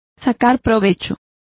Complete with pronunciation of the translation of profiting.